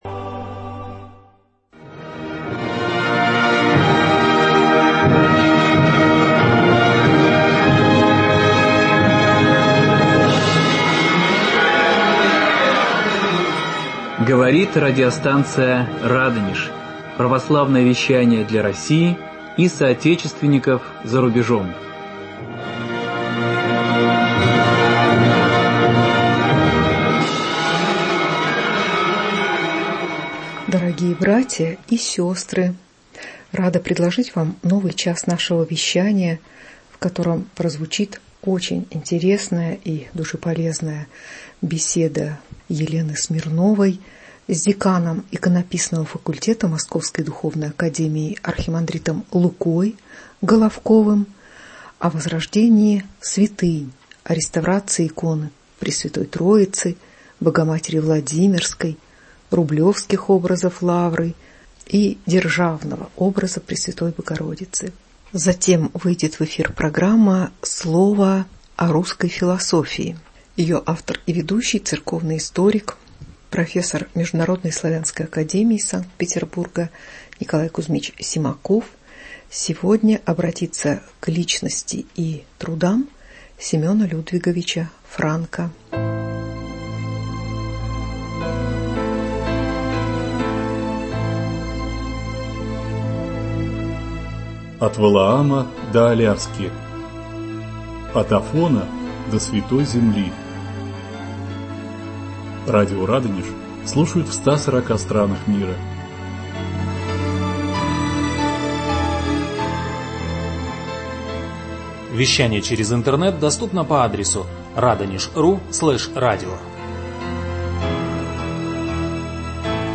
Философия сердца в миросозерцании Ф.М. Достоевского и И.А. Ильина. Беседа